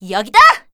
assassin_w_voc_bladerunner02.ogg